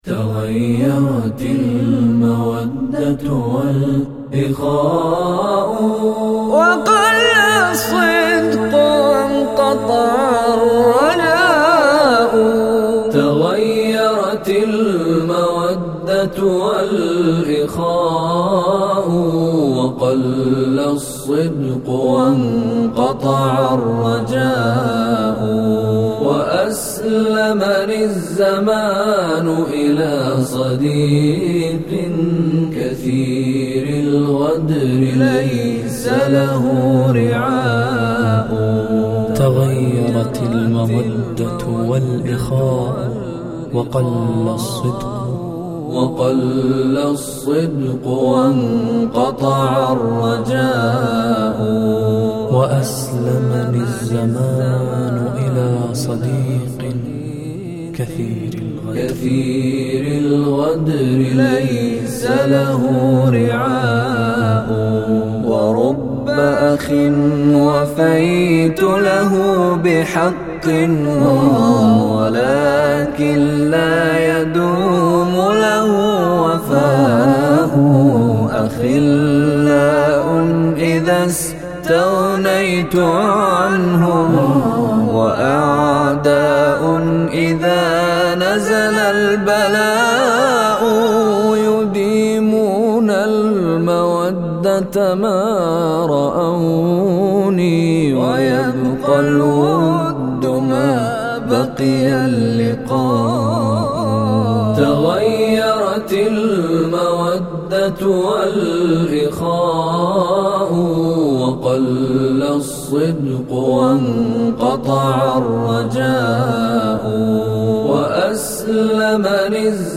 الاناشيد